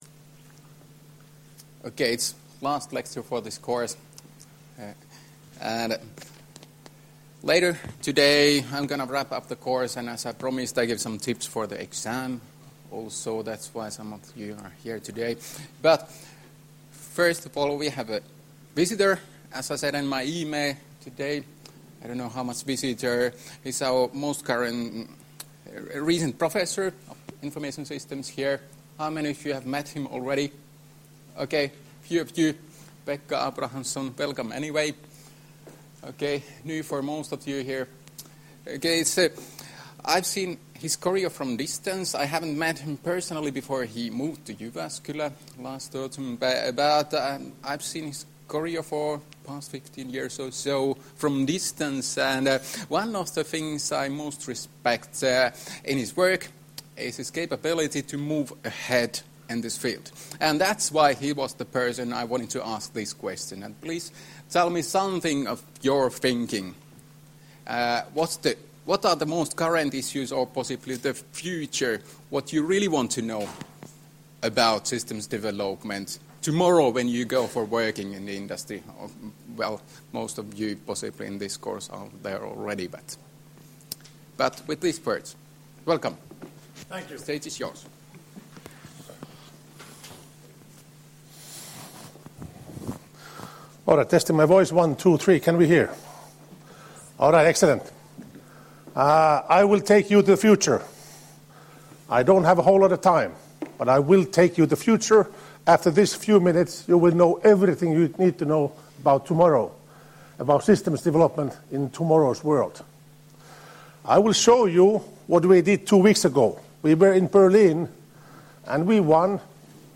Luento 27.2.2018 — Moniviestin